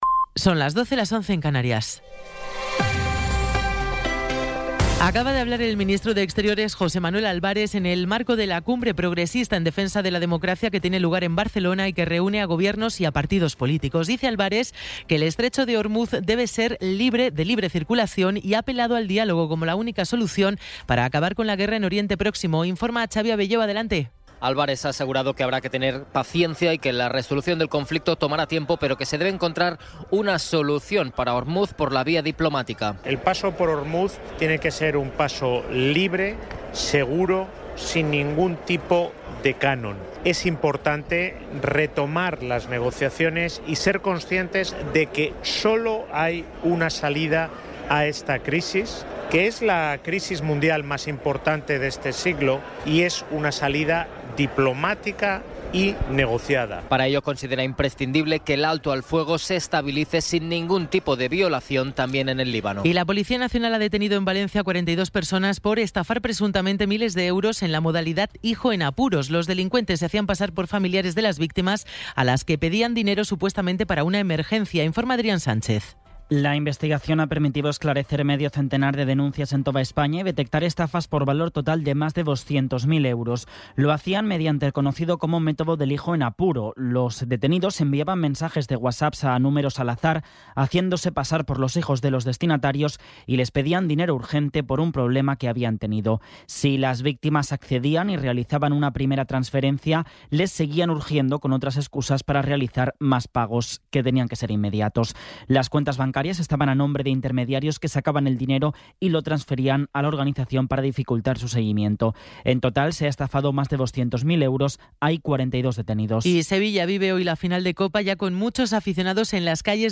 Resumen informativo con las noticias más destacadas del 18 de abril de 2026 a las doce.